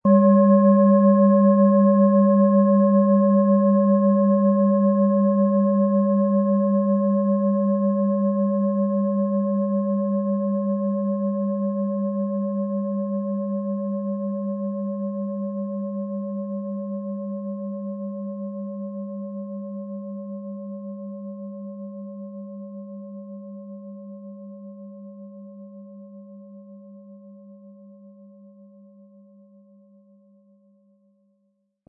Im Audio-Player - Jetzt reinhören hören Sie genau den Original-Ton der angebotenen Schale.
PlanetentöneTageston & Mars
SchalenformBihar
MaterialBronze